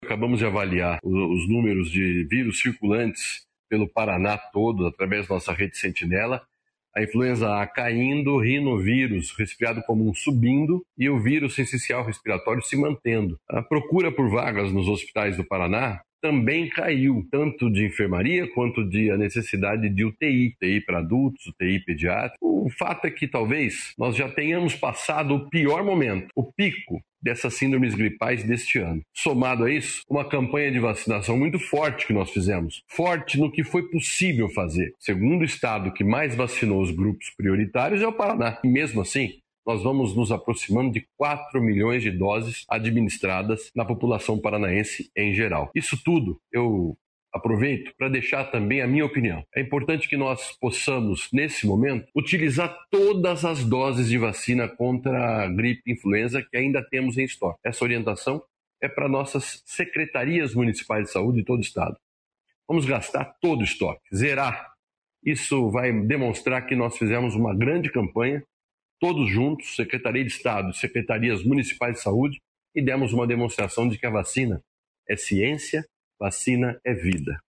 Sonora do secretário da Saúde, Beto Preto, sobre casos e internações por síndromes gripais